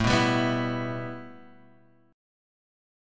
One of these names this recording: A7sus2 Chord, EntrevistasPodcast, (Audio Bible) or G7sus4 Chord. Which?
A7sus2 Chord